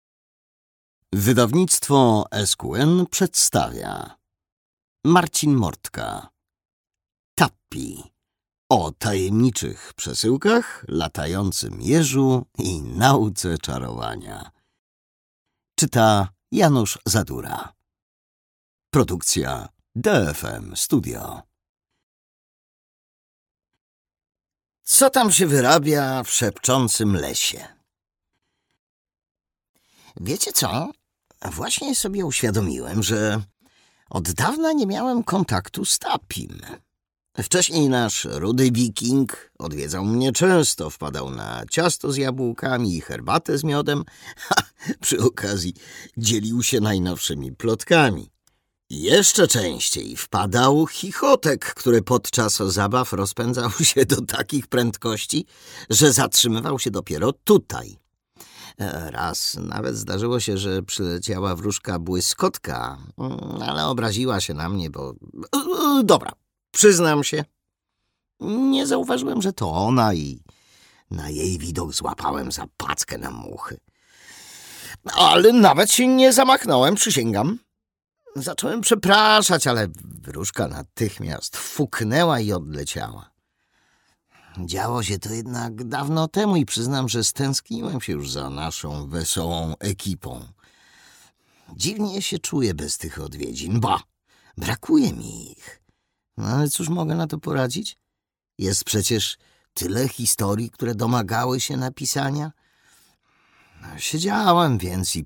Tappi. O tajemniczych przesyłkach, latającym jeżu i nauce czarowania | Audiobook w SQN Store